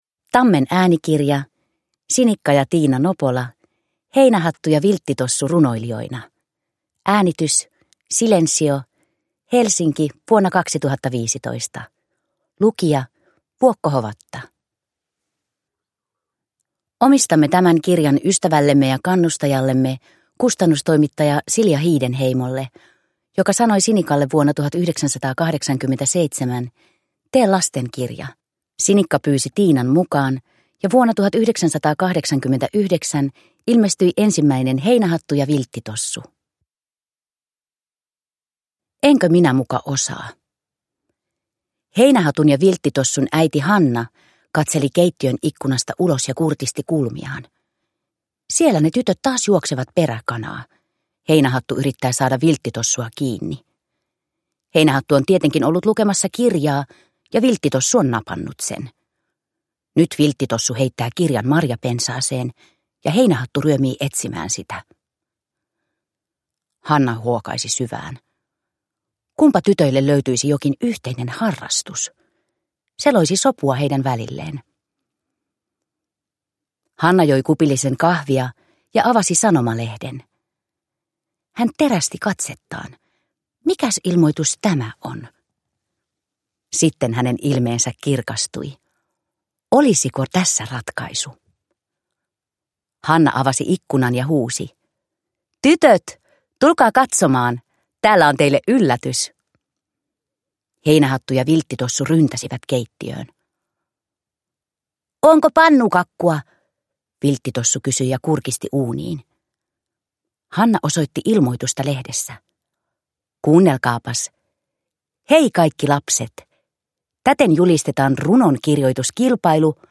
Heinähattu ja Vilttitossu runoilijoina – Ljudbok